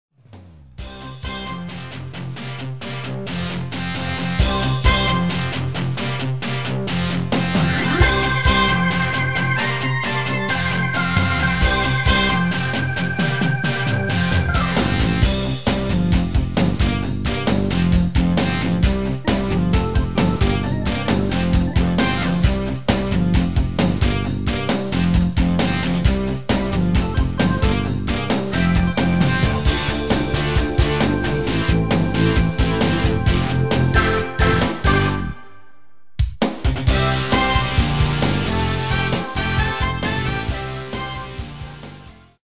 Demo/Koop midifile
Genre: Pop & Rock Internationaal
- Vocal harmony tracks
Demo = Demo midifile